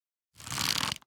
Minecraft Version Minecraft Version snapshot Latest Release | Latest Snapshot snapshot / assets / minecraft / sounds / item / crossbow / quick_charge / quick1_3.ogg Compare With Compare With Latest Release | Latest Snapshot